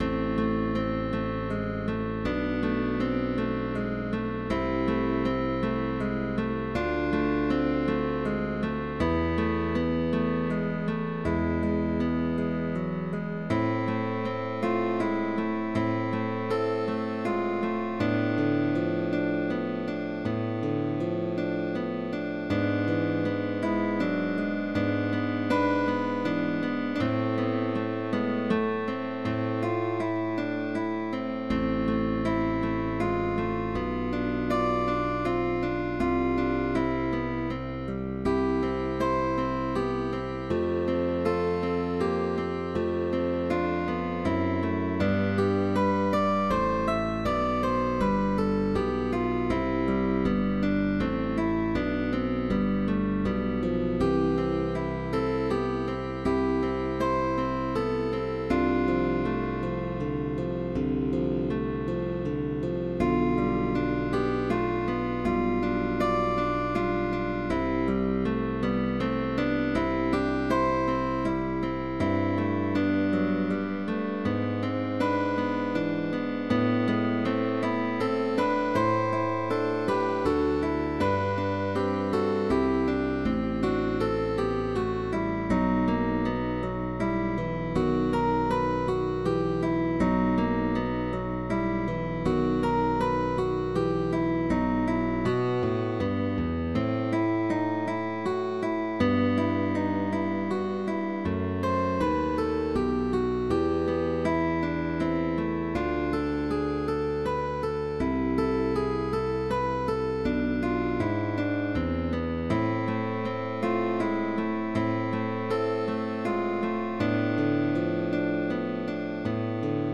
GUITAR TRIO
Baroque